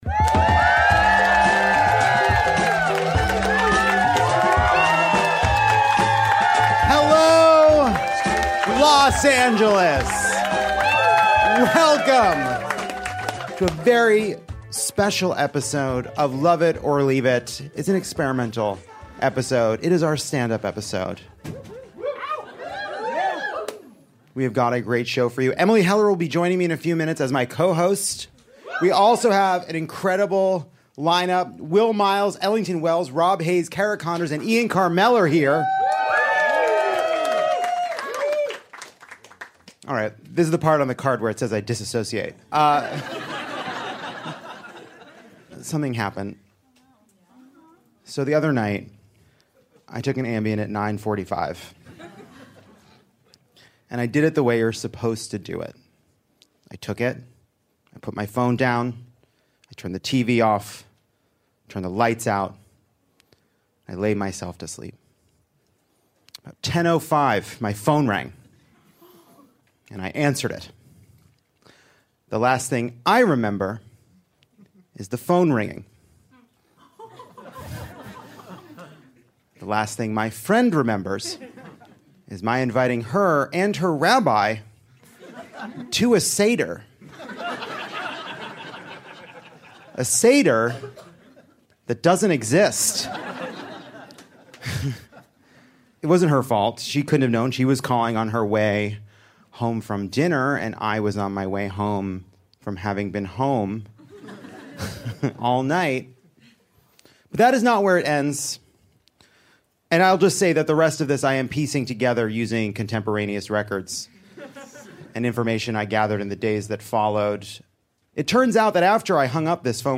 Lovett Or Leave It brings a stellar array of comedy’s best and brightest to the Dynasty Typewriter stage with a special stand up episode.